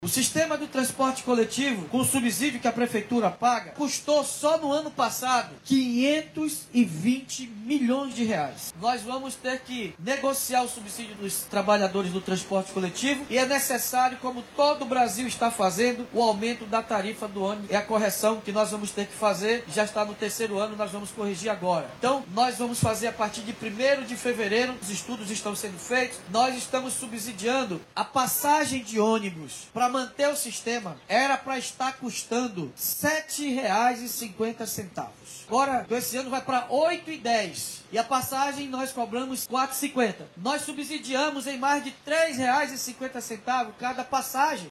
Durante a entrega de novos ônibus, na manhã desta sexta-feira 03/01, o Prefeito de Manaus, Davi Almeida, anunciou o aumento da tarifa do Transporte Coletivo, para este ano.